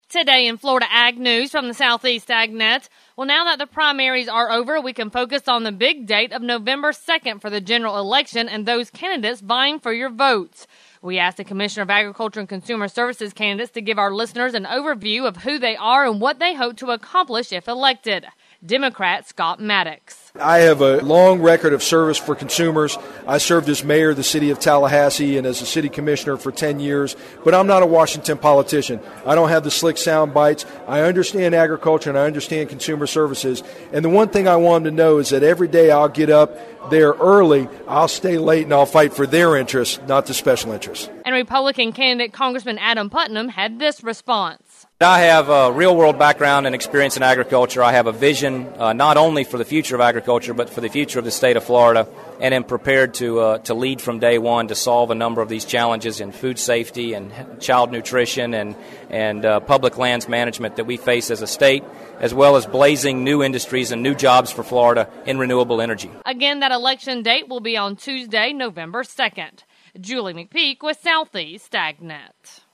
In this report we asked the Commissioner of Agriculture and Consumer Services candidates, Scott Maddox and Adam Putnam, to give our listeners an overview of who they are and what they hope to accomplish if elected.